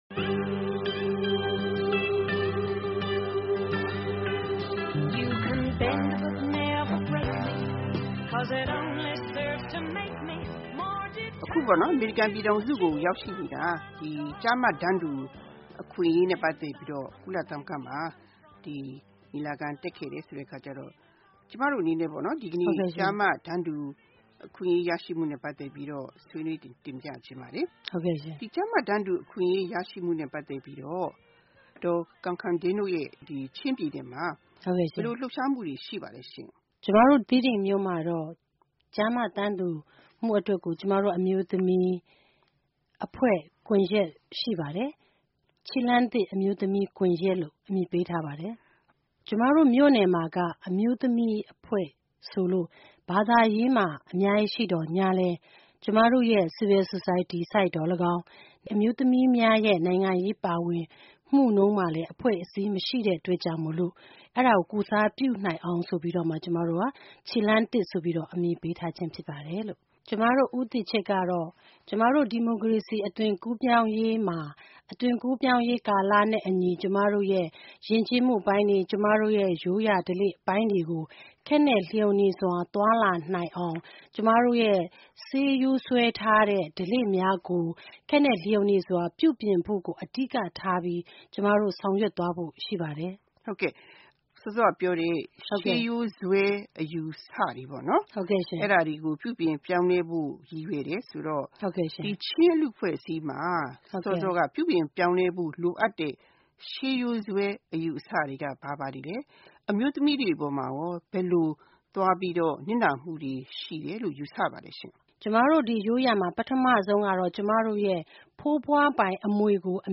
တွေ့ဆုံမေးမြန်းထား